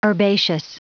Prononciation du mot herbaceous en anglais (fichier audio)
Prononciation du mot : herbaceous